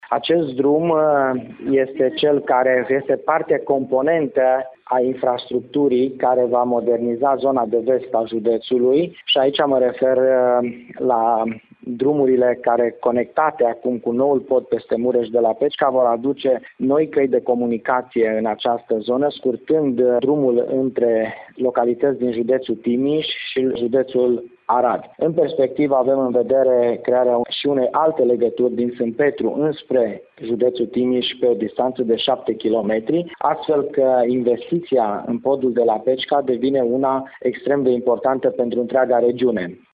Preşedintele Consiliului Judeţean Arad, Nicolae Ioţcu, spune că  podul va face de asemenea legătura şi cu judeţul vecin, în condiţiile în care în strategia de dezvoltare a judeţului este prevăzut şi drumul dintre Sânpetru German şi localitatea Gelu din Timiş.